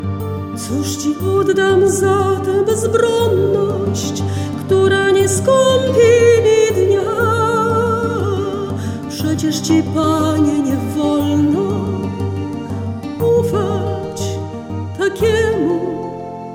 pieśniarka, instruktorka muzyki